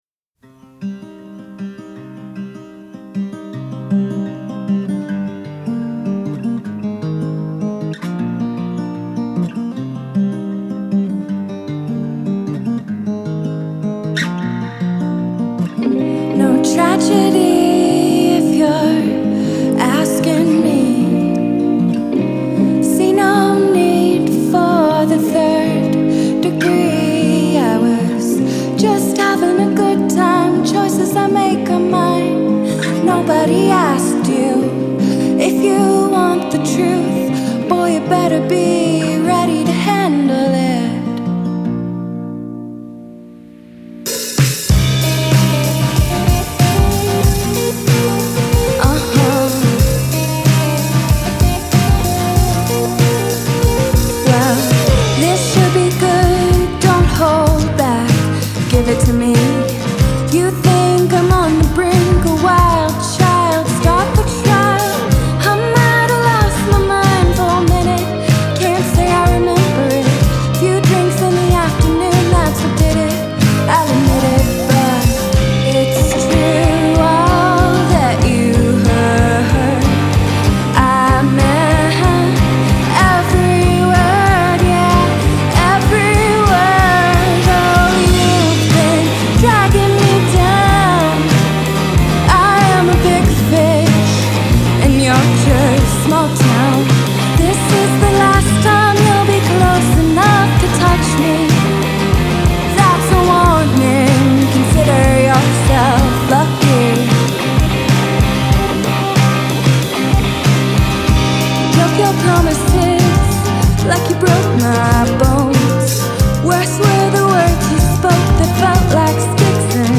dark story songs
electric guitar
drums
bass